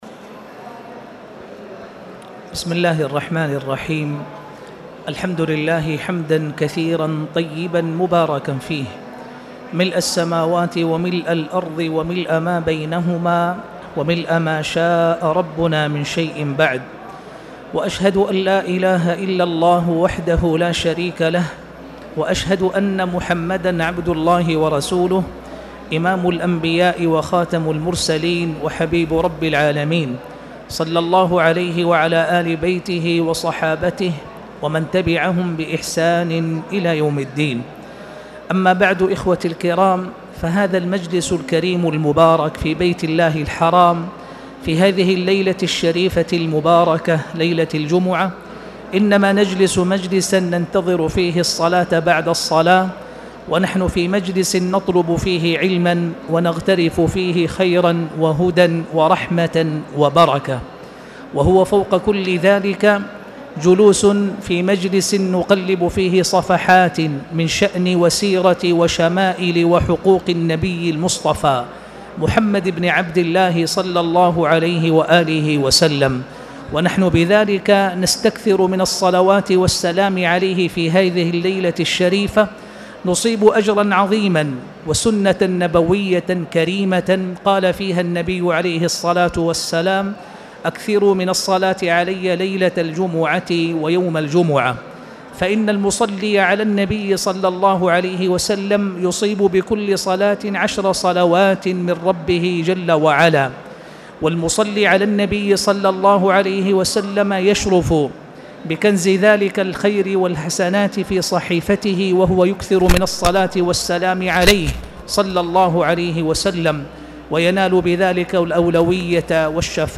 تاريخ النشر ٢ رجب ١٤٣٨ هـ المكان: المسجد الحرام الشيخ